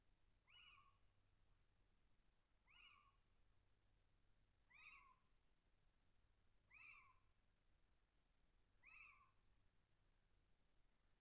Cri
L'un des cris les plus typiques de la chevêche est un koui-you sonore qu'elle lance surtout le soir mais aussi quand elle est excitée. Elle pousse aussi des kifkifkif répétés. Le chant du mâle est un coubou interrogatif, un peu plaintif.
Cri_chevêche_d'Athéna.wav